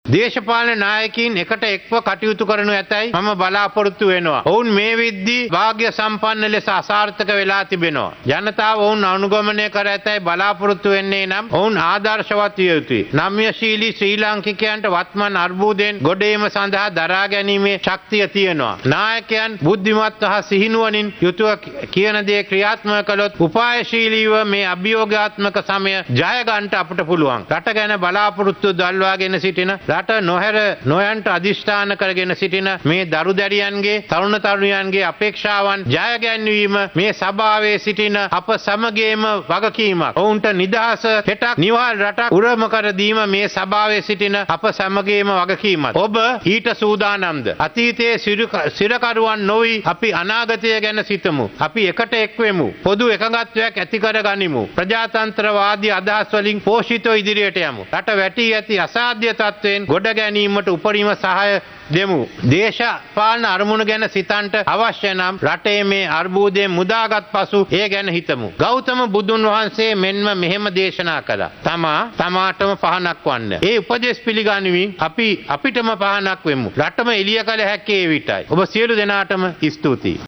බදු බර අහෝසි කිරීමට හැකියාවක් නොමැති බව ජනාධිපති රනිල් වික්‍රමසිංහ මහතා රජයේ ප්‍රතිපත්ති ප්‍රකාශය ඉදිරිපත් කරමින් පැවසුවා.